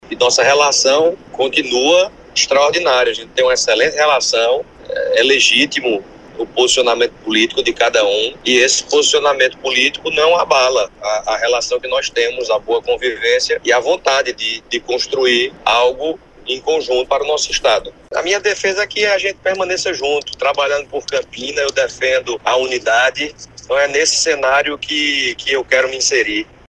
O comentário de Pedro foi registrado pelo programa Correio Debate, da 98 FM, de João Pessoa, nesta quinta-feira (13/07).